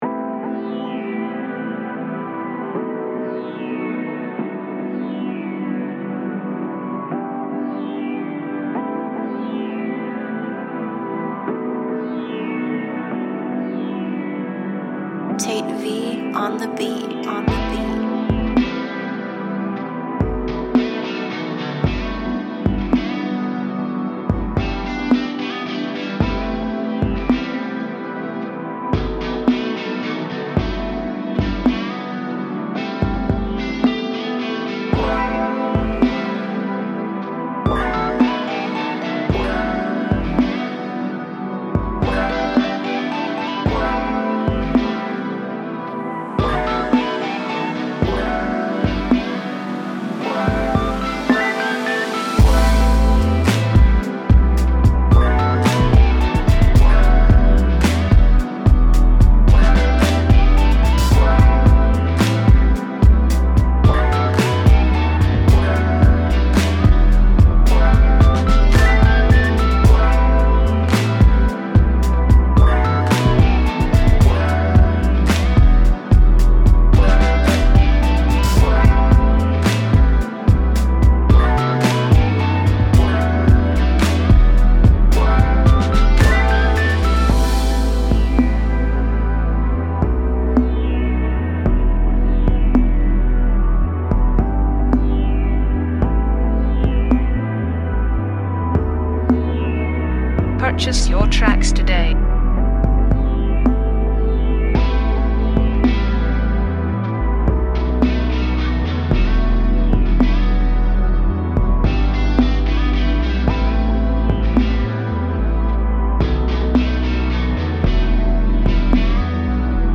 Drum, Piano